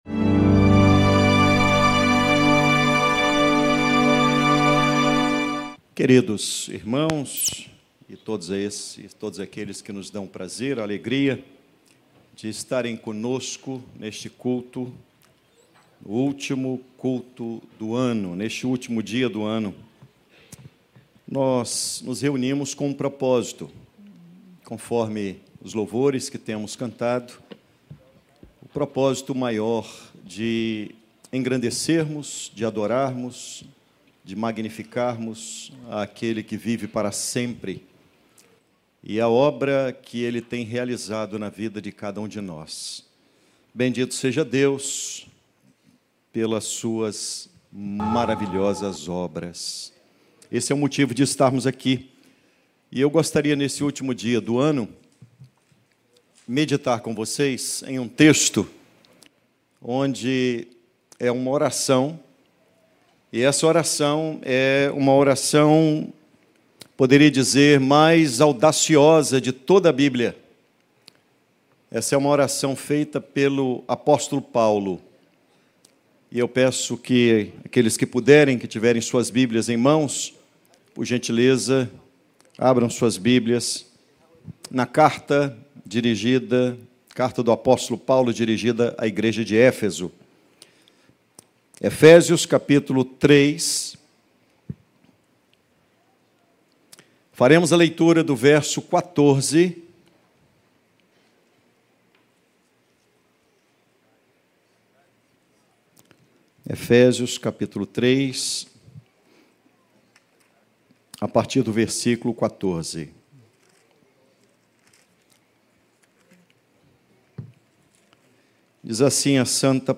Descubra como viver a plenitude do amor de Cristo e experimentar o poder transformador de Deus. Inspire-se com este sermão baseado em Efésios 3:14-21.